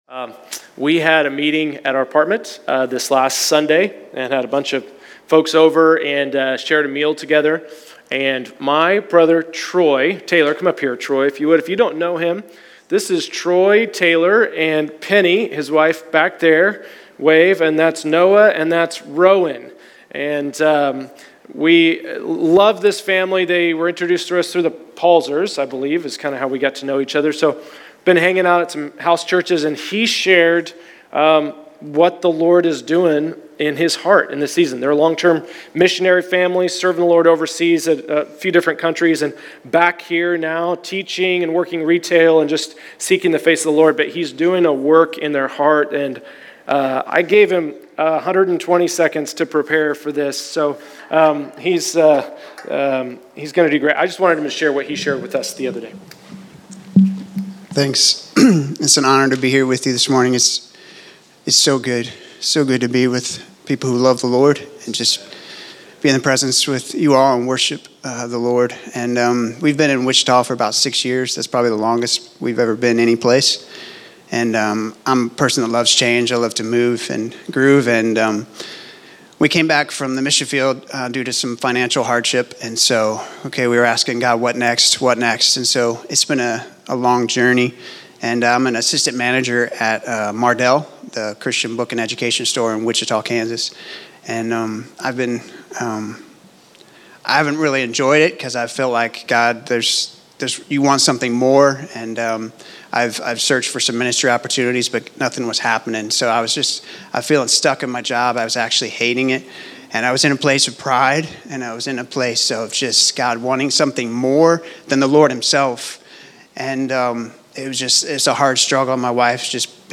Testimony of Healing
Category: Testimonies